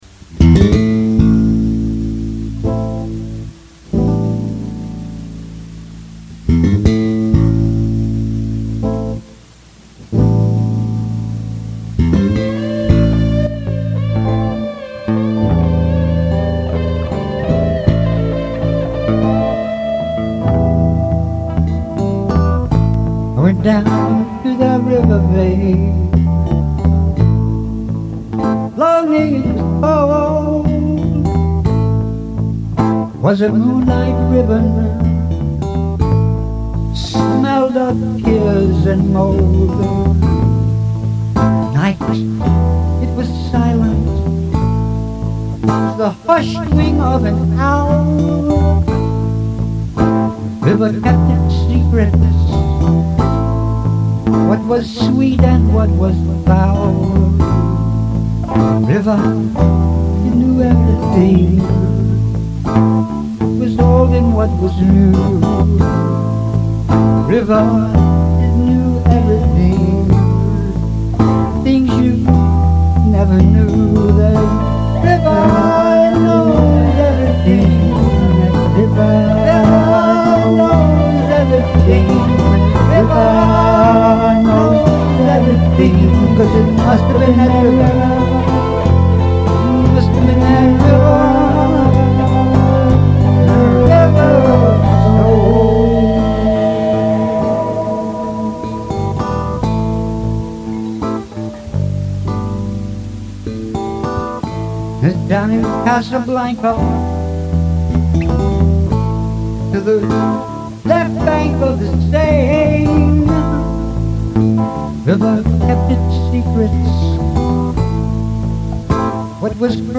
sorry about the noise